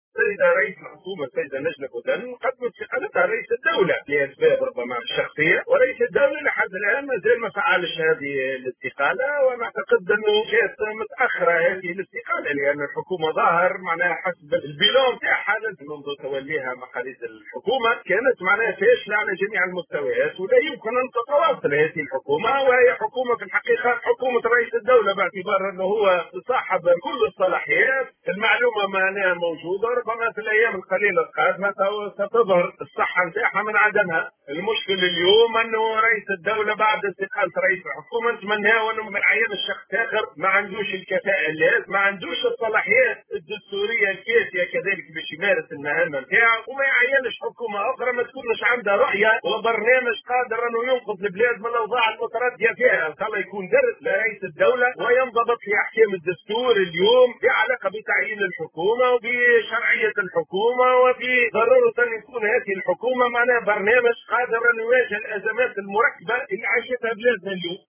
S’exprimant, ce jeudi 12 mai 2022 au micro de Tunisie Numérique, Chaouachi a précisé que cette décision a été prise pour “des raisons personnelles” et que le chef de l’Etat n’a pas activé  pour le moment la démission de Bouden.